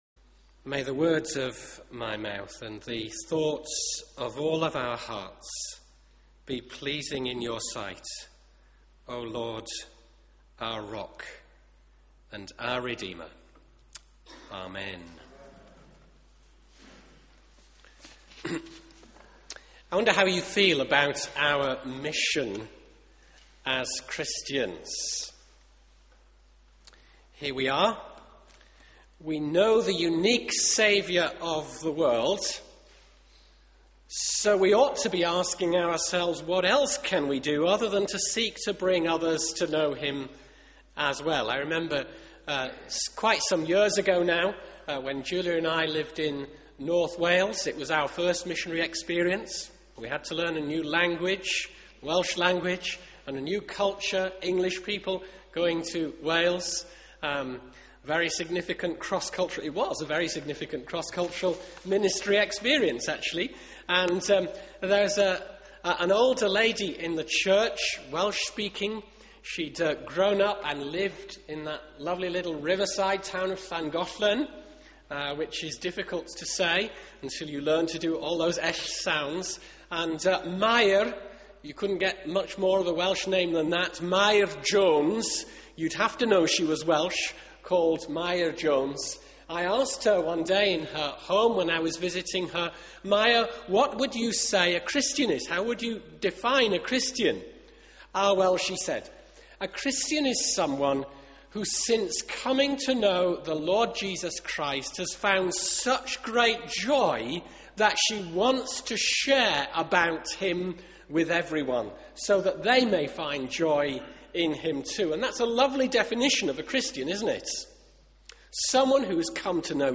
Media for 9:15am Service on Sun 05th Feb 2012 09:15 Speaker
Theme: Mission Possible Sermon